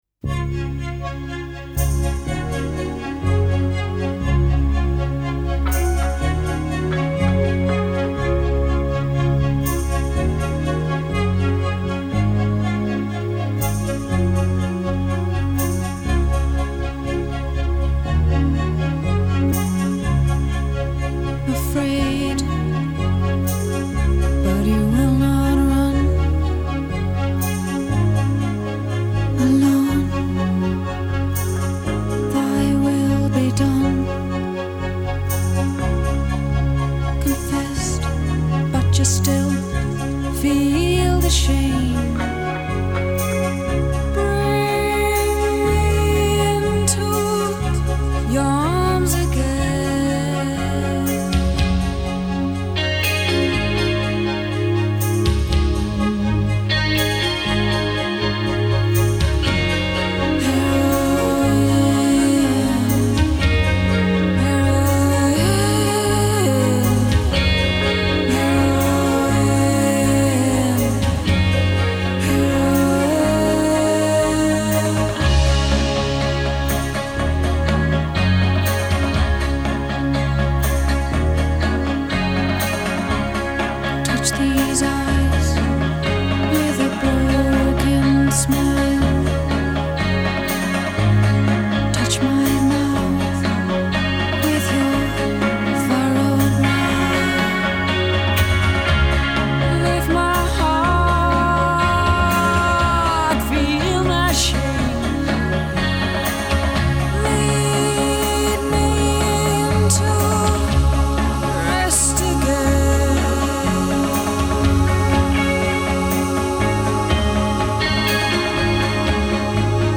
From the original soundtrack